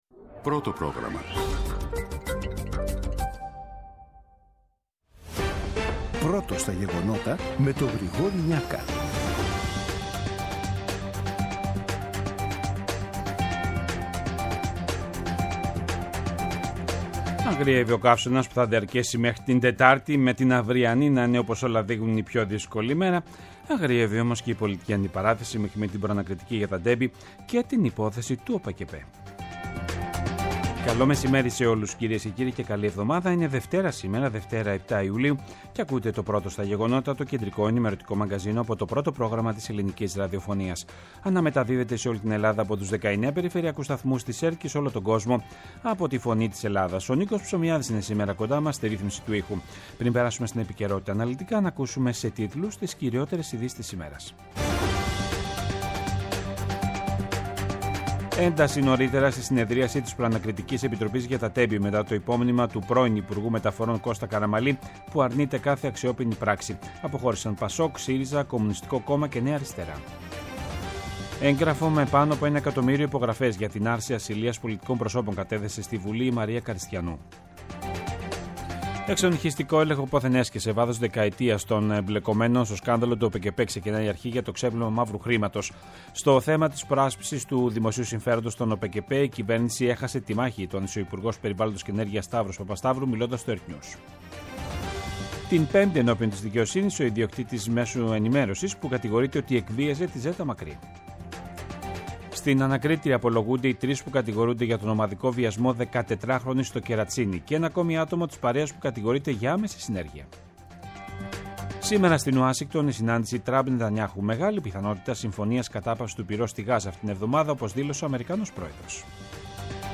Το αναλυτικό ενημερωτικό μαγκαζίνο του Α΄ Προγράμματος στις 14:00. Με το μεγαλύτερο δίκτυο ανταποκριτών σε όλη τη χώρα, αναλυτικά ρεπορτάζ και συνεντεύξεις επικαιρότητας.
Αναλυτικο Δελτιο Ειδησεων